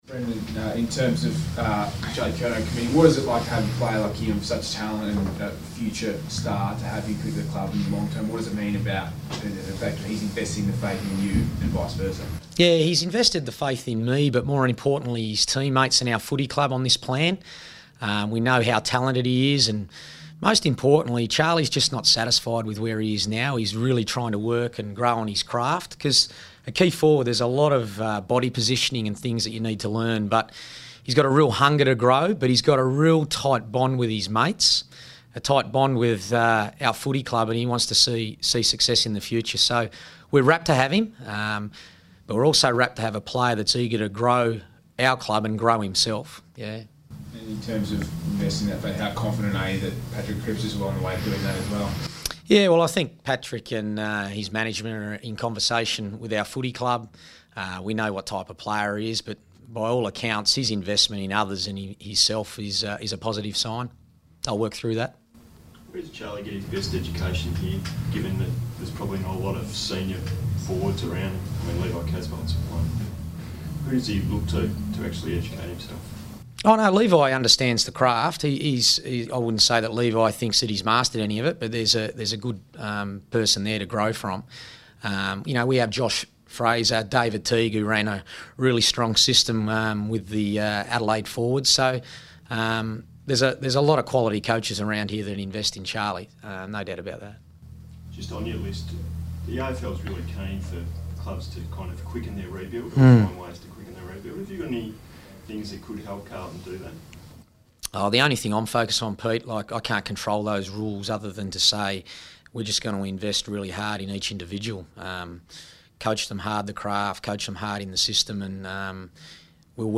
Brendon Bolton press conference | June 23
Carlton coach Brendon Bolton speaks to the media on the eve of the Blues' clash against Collingwood.